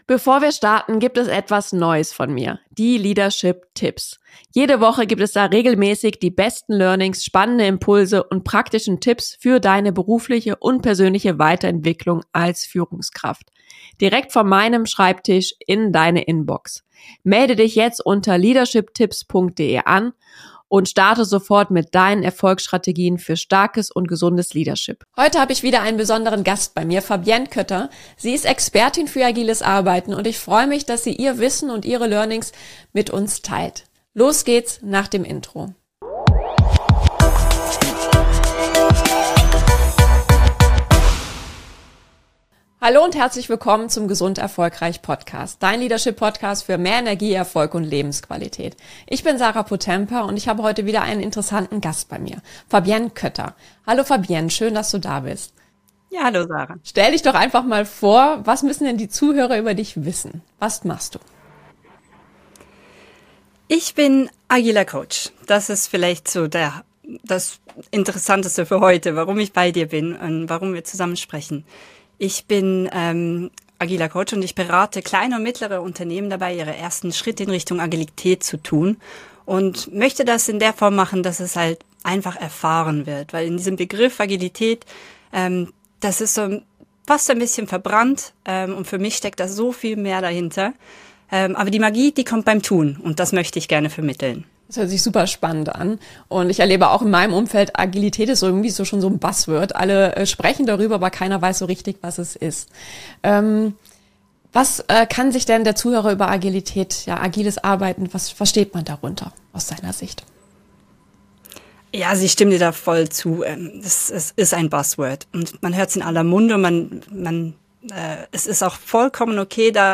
Nr. 49 - Wie du mit agilen Methoden dein Teamerfolg verbesserst - Interview